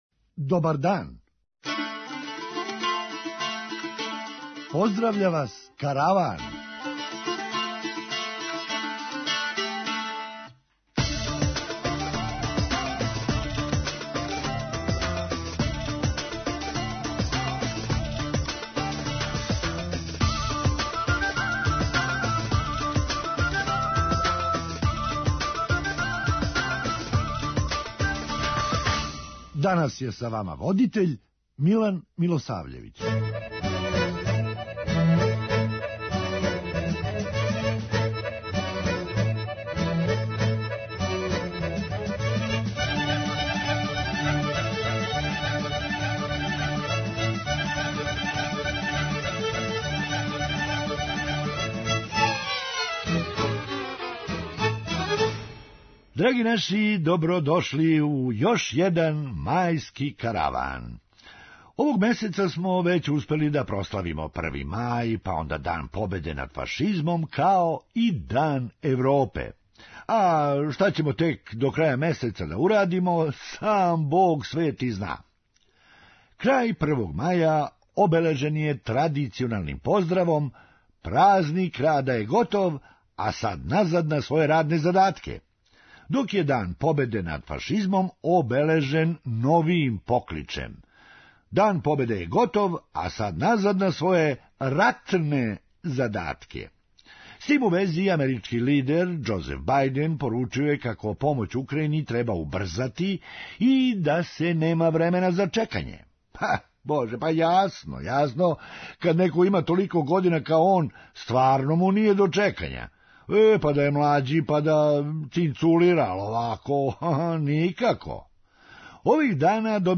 Хумористичка емисија
Меч је био тежак, јер су оба тима играла пред домаћом публиком. преузми : 8.88 MB Караван Autor: Забавна редакција Радио Бeограда 1 Караван се креће ка својој дестинацији већ више од 50 година, увек добро натоварен актуелним хумором и изворним народним песмама.